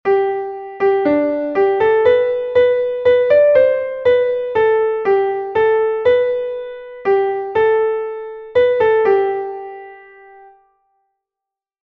Audio files: MIDI,
Key: A♭ Major
Source: Russian Folk-song